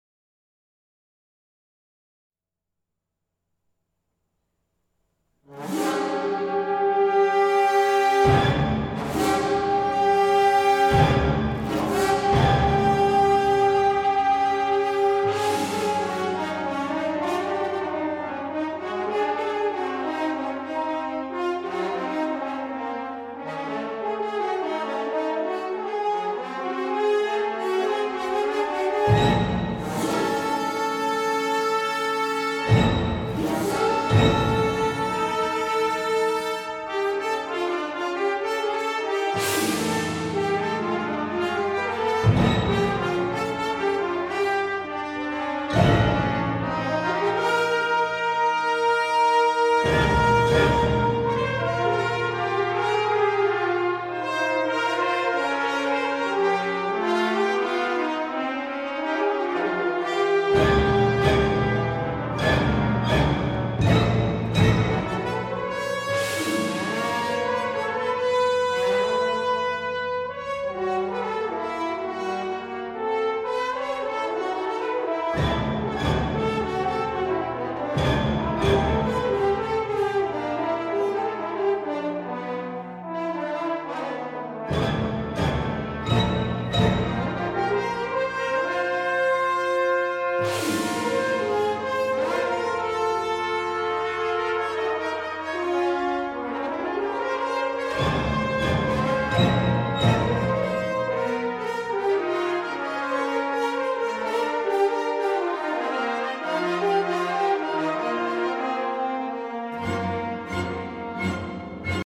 Concerto for Orchestra 管弦樂協奏曲 32 minutes